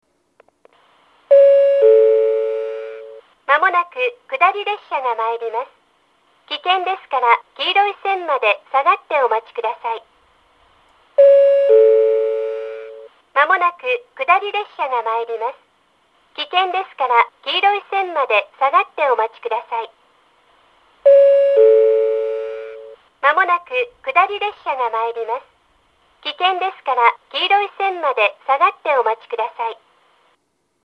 １番線接近放送
（長野より）   長野よりの接近表示機から流れる放送です。
amori-1bannsenn-sekkinn-nagano.mp3